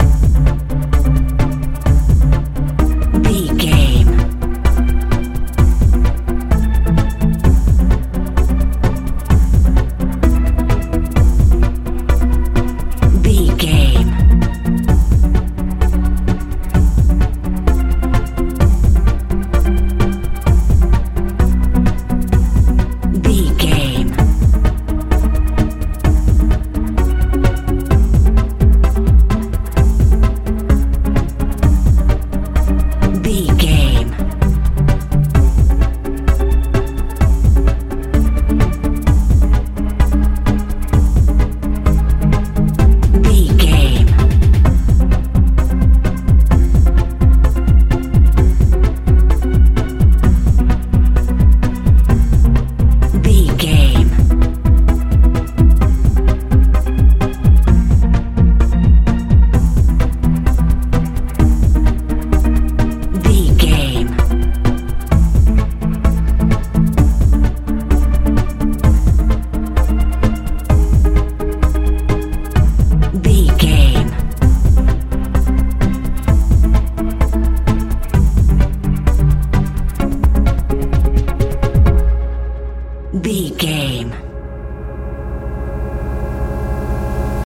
modern dance
Aeolian/Minor
intense
powerful
bass guitar
synthesiser
drums
tension
ominous
futuristic